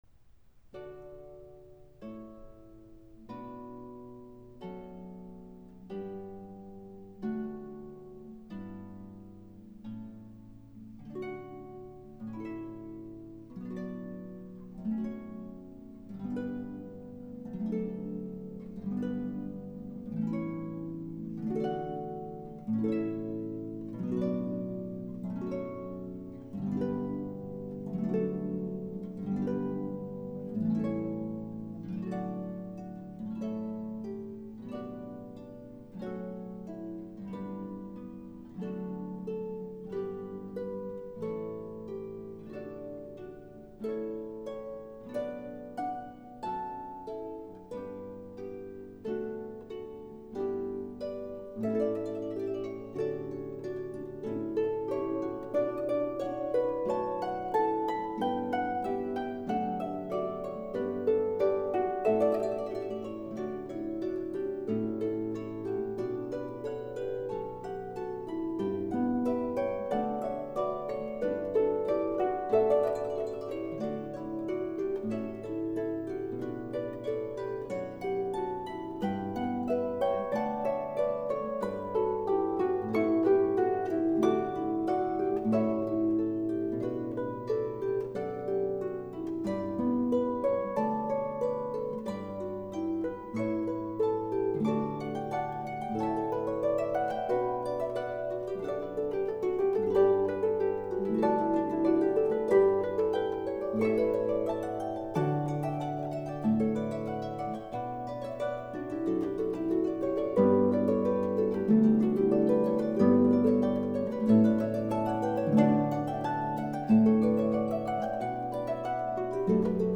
Solo Harp